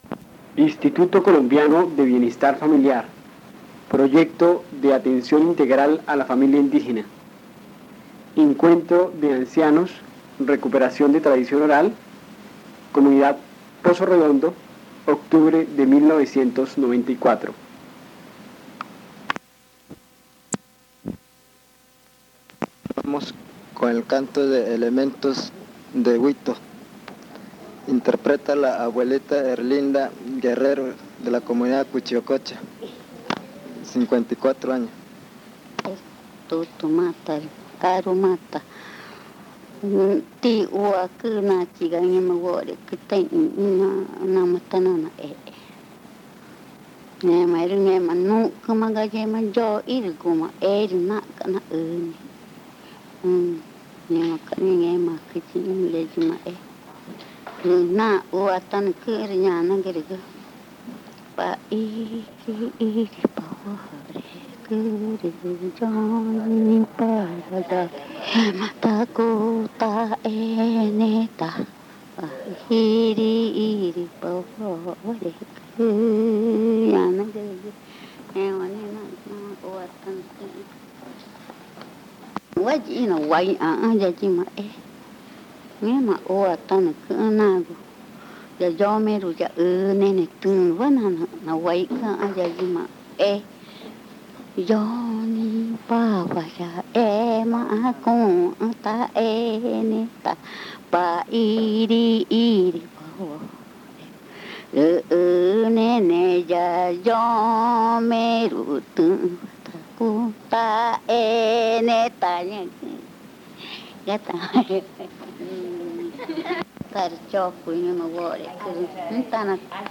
Canciones del ritual de la pubertad magütá y tres cuentos
Se grabaron un total de tres casetes, de los cuales este es el tercer volumen.